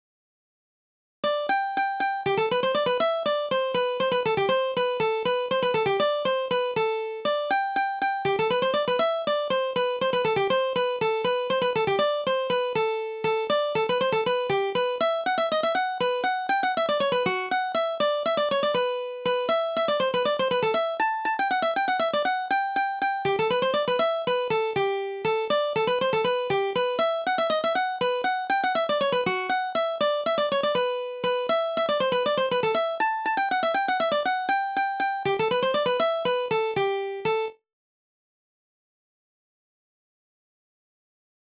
DIGITAL SHEET MUSIC - MANDOLIN SOLO